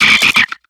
Grito de Nuzleaf.ogg
Grito_de_Nuzleaf.ogg